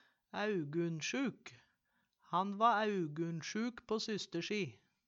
æugunsjuk - Numedalsmål (en-US)